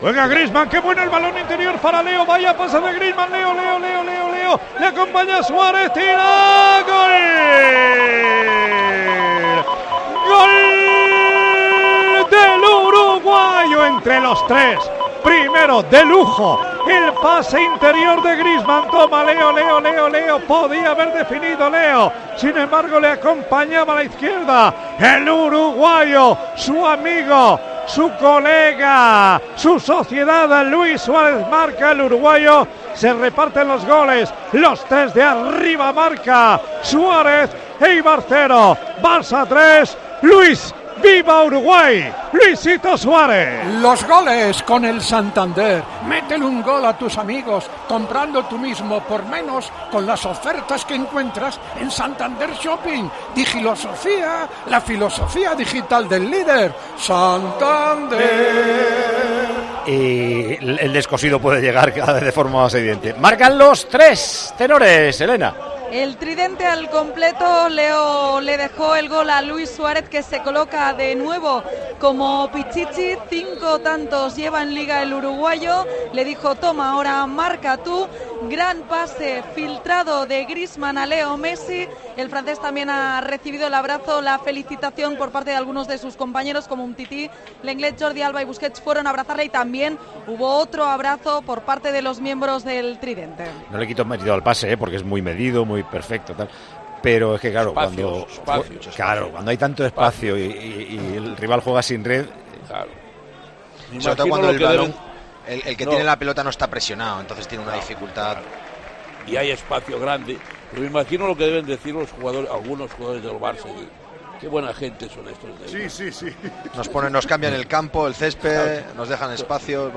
ANÁLISIS DE LOS COMENTARISTAS